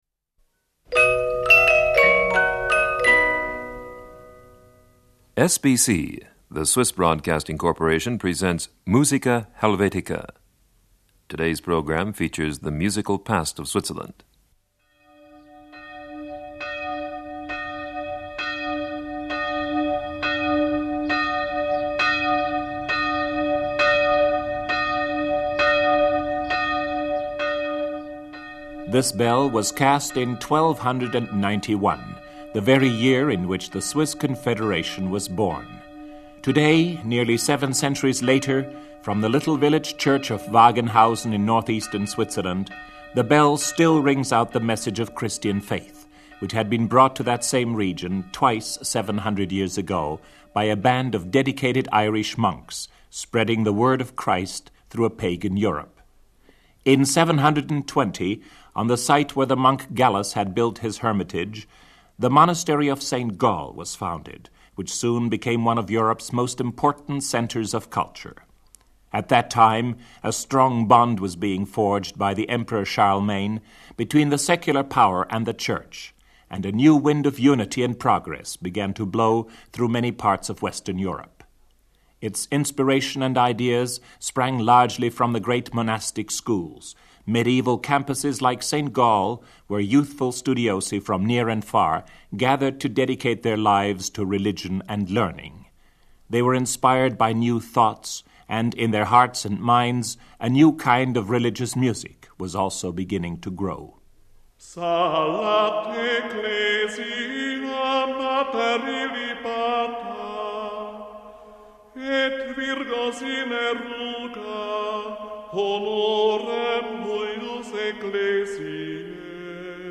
It outlines the development of serious music in Switzerland from earliest liturgic songs to the classical sounds of an 18th century opera buffa on a rustic Swiss theme.